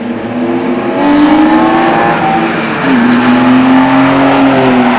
Wave file of Supersprint and Dinan Intake